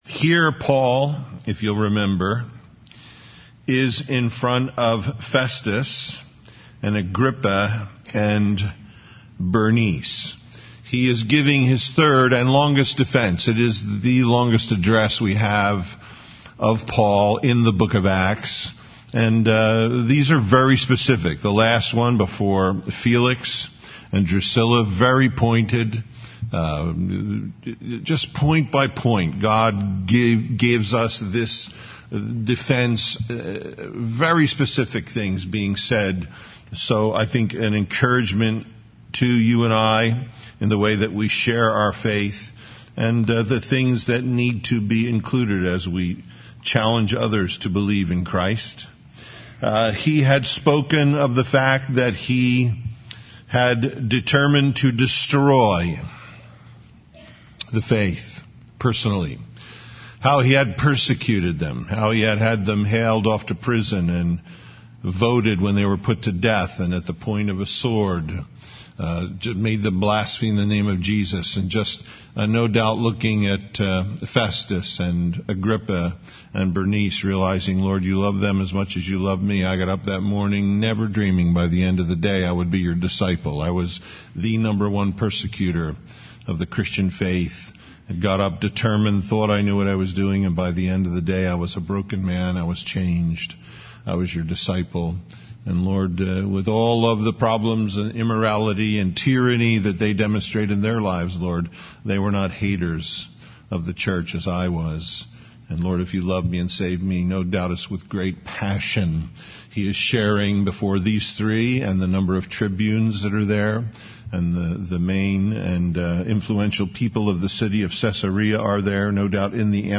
Acts 26:24-27:44 Listen Download Original Teaching Email Feedback 26 27 And as he thus spake for himself, Festus said with a loud voice, Paul, thou art beside thyself; much learning doth make thee mad.